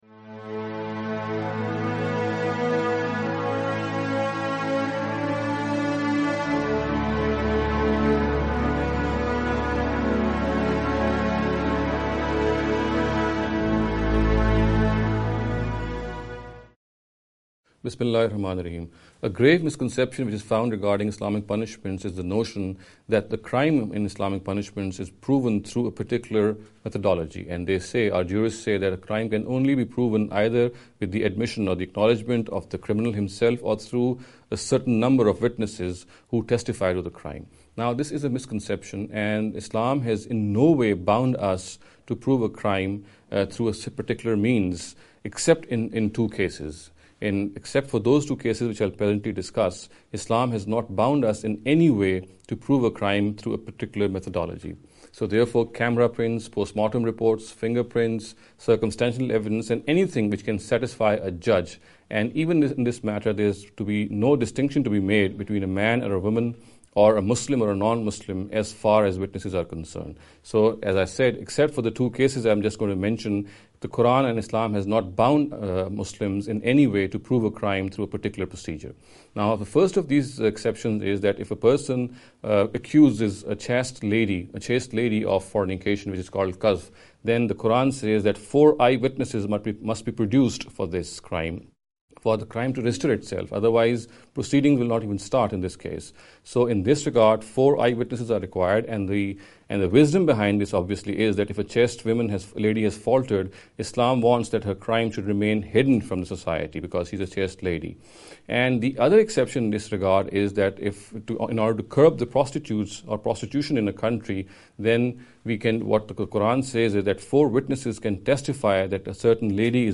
This lecture series will deal with some misconception regarding the Islamic Punishments.